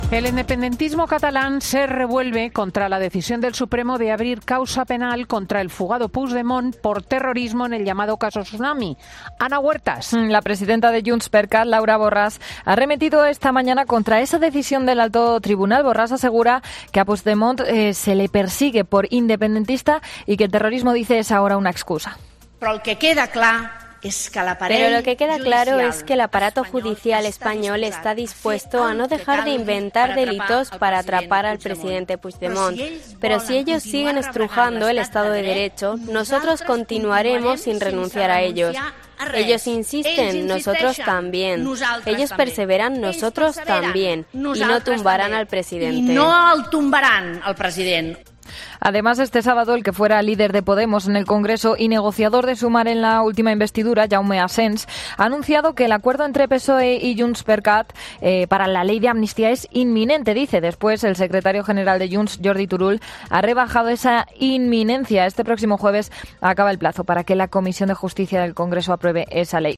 Borràs ha intervenido este sábado ante el consejo nacional de JxCat, máximo órgano del partido entre congresos, que se ha reunido en Llagostera (Gerona), y que prevé aprobar el reglamento de primarias para las próximas elecciones europeas, previstas para el 9 de junio.